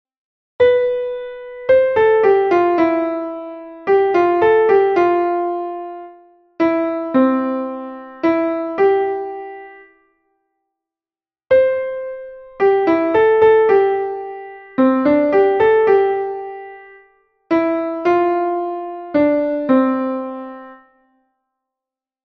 gif accordionLook at the image and listen to the sound (which only shows a metronome rhythmic pattern) to assimilate how syncopation works.